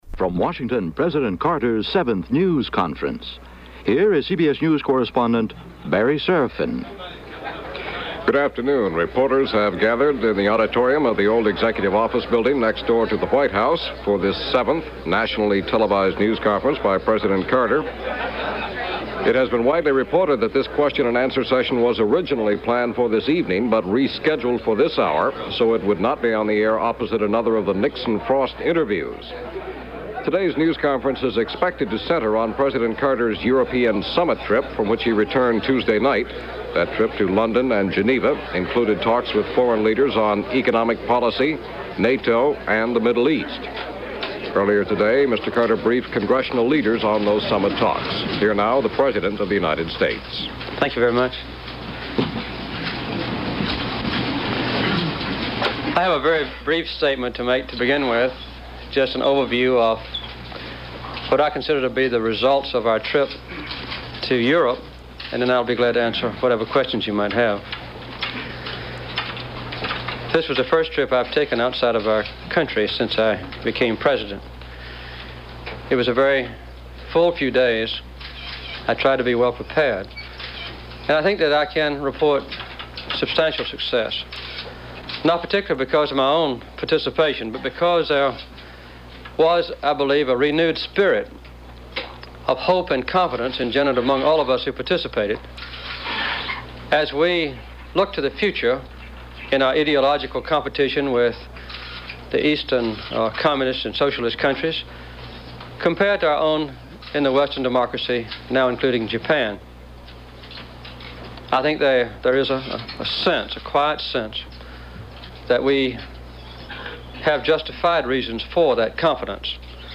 Seventh press conference